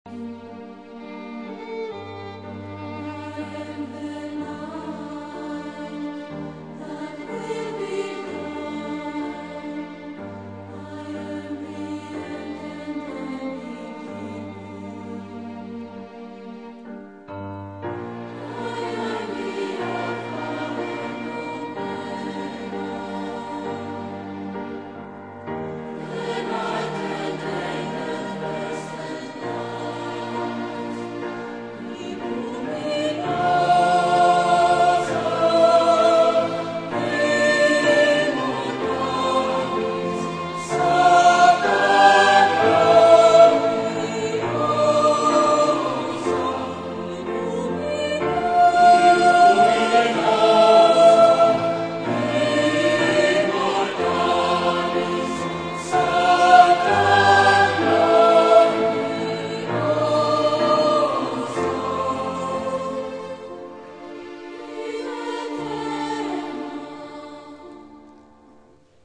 Χορωδιακή συναυλία "Τραγούδια Προσευχής"
ΖΩΝΤΑΝΗ ΗΧΟΓΡΑΦΗΣΗ